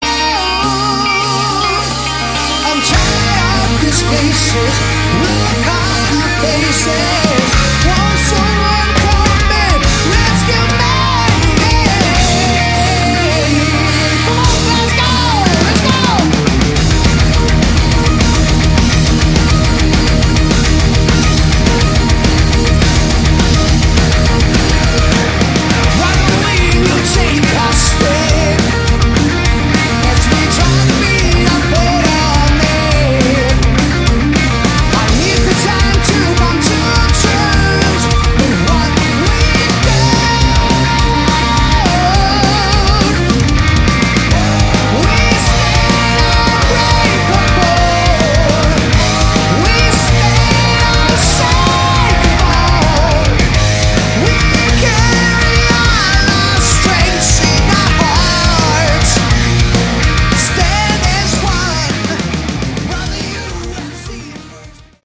Category: Hard Rock
lead vocals
guitars
bass, vocals
keys, vocals
drums